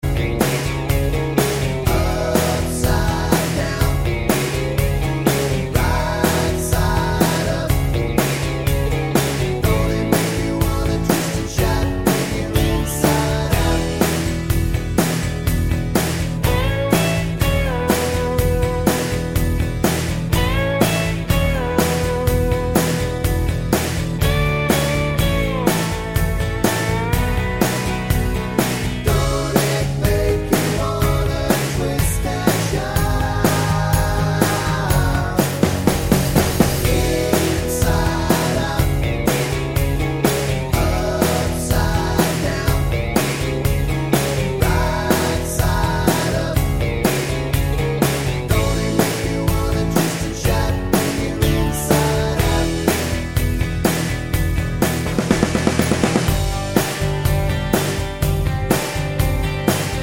With Two Part Harmonies Pop (1980s) 3:34 Buy £1.50